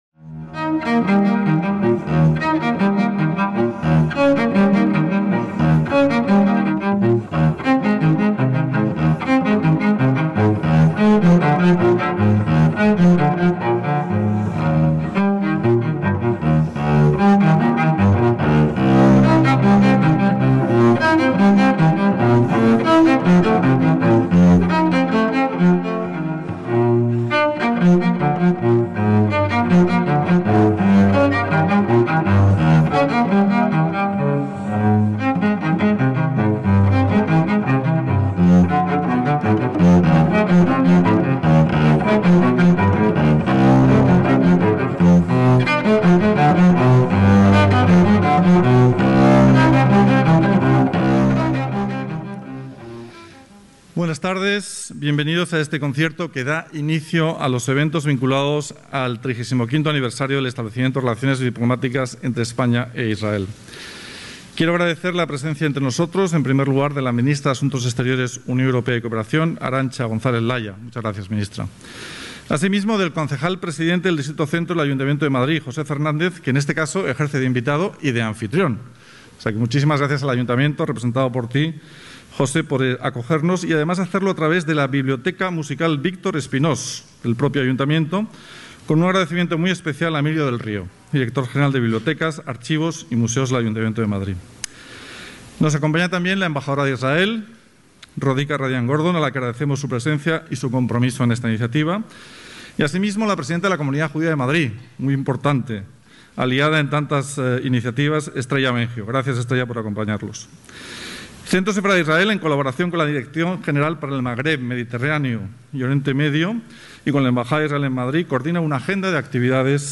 Concierto "En torno a Pau Casals" (Auditorio del Centro Conde Duque, Madrid, 22/6/2021)
ACTOS EN DIRECTO
que en “A Casals” realiza una aproximación vanguardista al característico dúo que conforman violonchelo y piano y